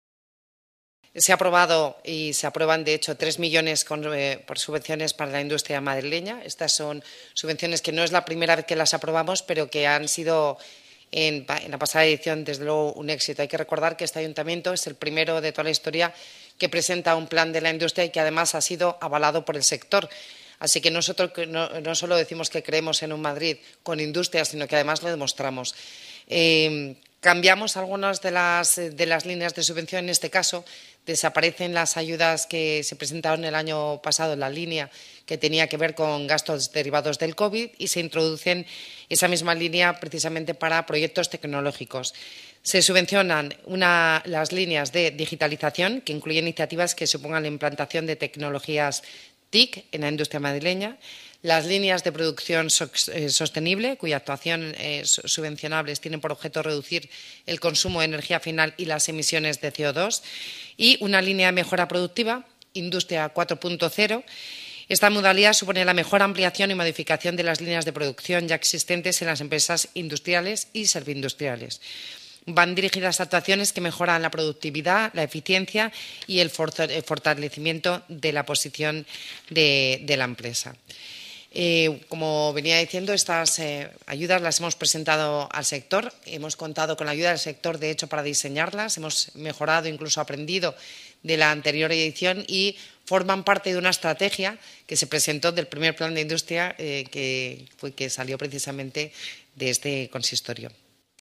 Nueva ventana:Así lo ha explicado la vicealcaldesa de Madrid, Begoña Villacís